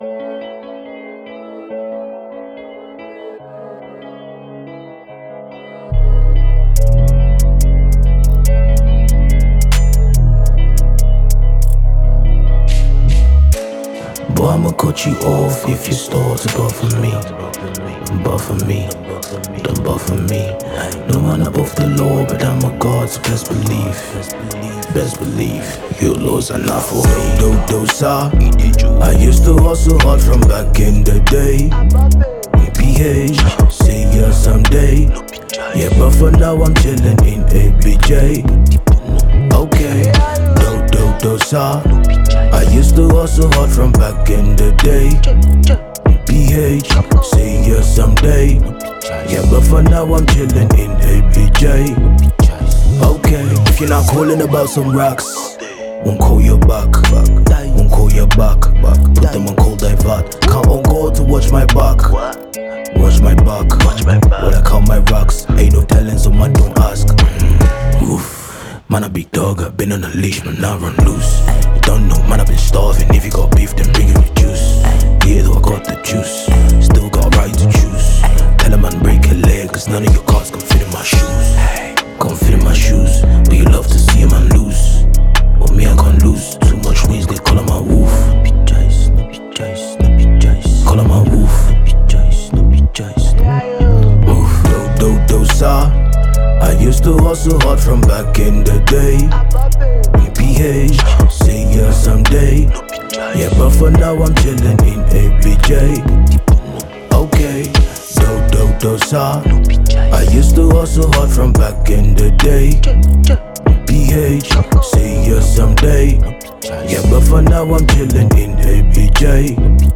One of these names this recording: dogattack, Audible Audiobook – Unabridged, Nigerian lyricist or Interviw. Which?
Nigerian lyricist